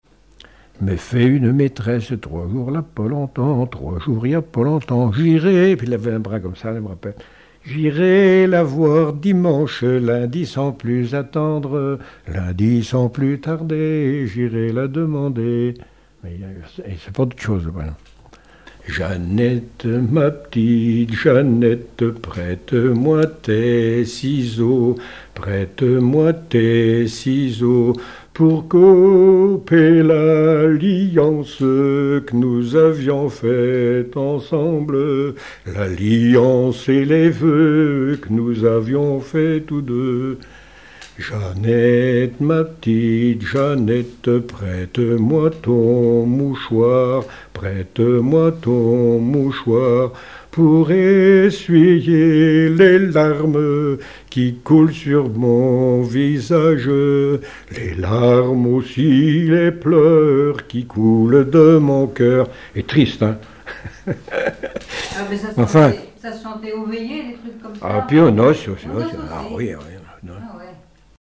Chanté aux veillées et aux noces
Genre strophique
Pièce musicale inédite